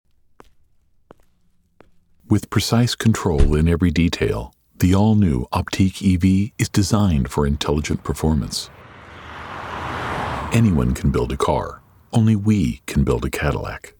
Just some guy with a deep, authoritative voice
Demo Luxury Car VO
Have a big Whisper Room booth, MKH 416, TLM 49.